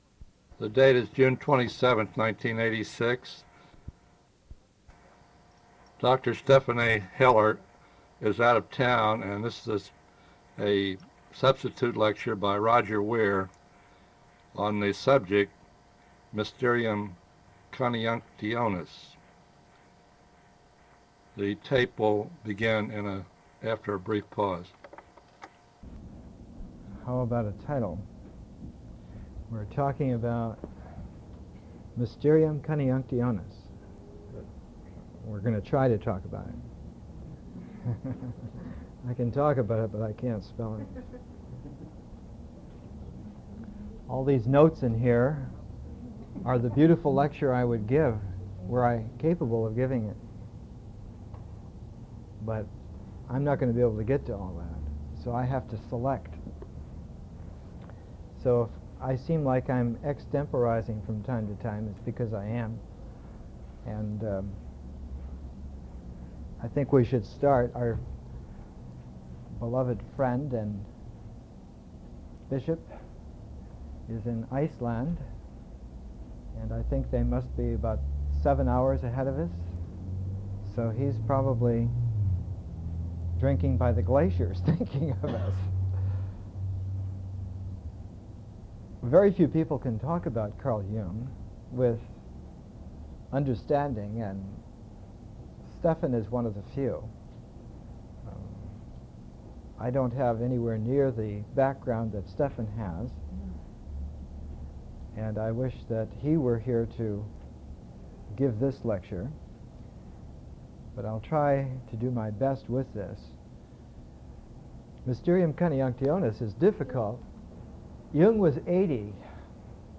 Single presentation